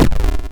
explode.wav